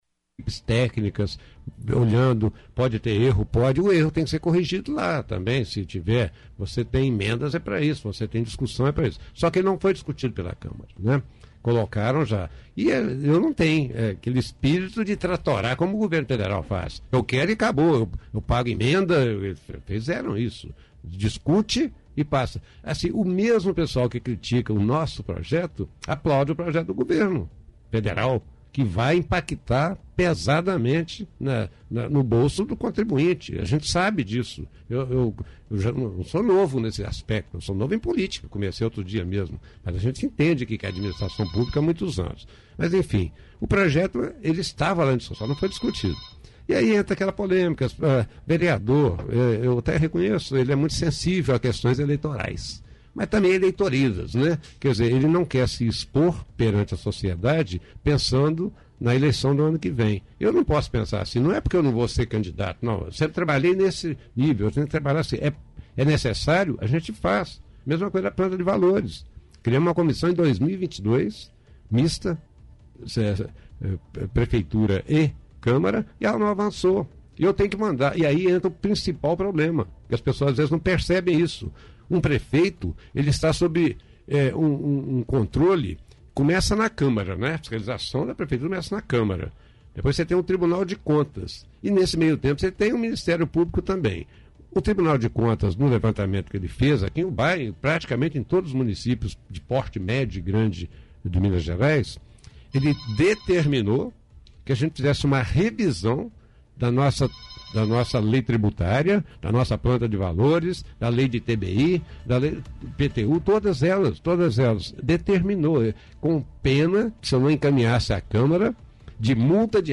Em entrevista à Rádio Ubaense, no Programa Fato do dia